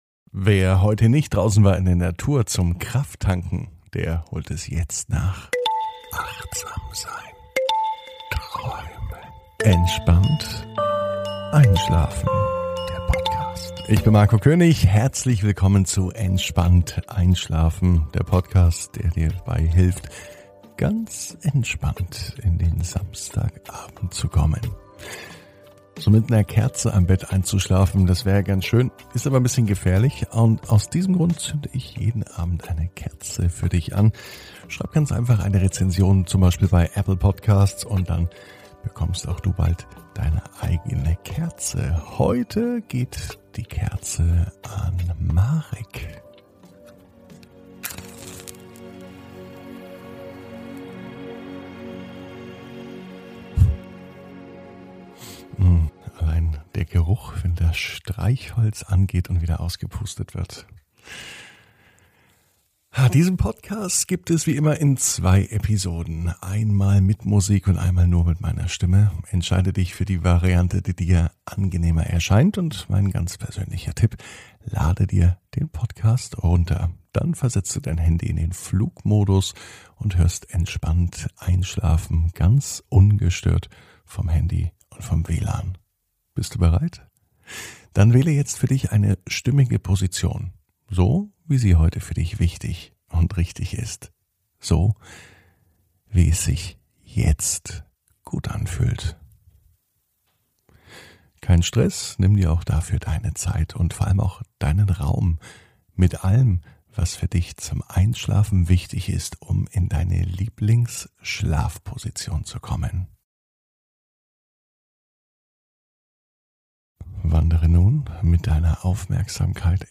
(ohne Musik) Entspannt einschlafen am Samstag, 05.06.21 ~ Entspannt einschlafen - Meditation & Achtsamkeit für die Nacht Podcast